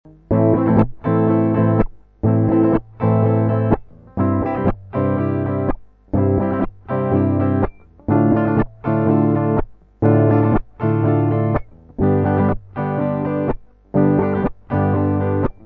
Со второго куплета бой немного другой: